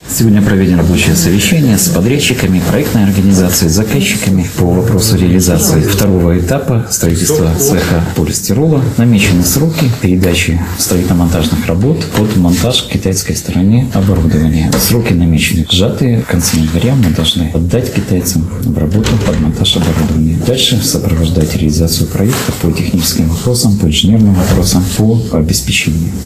Сегодня состоялось совещание, посвящённое реализации второго этапа проекта, с участием замглавы области Вадима Ольшевского и председателя Барановичского горисполкома Максима Антонюка. В фокусе встречи — ход выполнения работ, текущие задачи и дальнейшие этапы, — отметил Вадим Ольшевский.